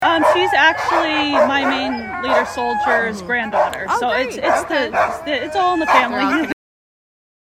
Current Location: Downtown Anchorage at the Ceremonial Iditarod Start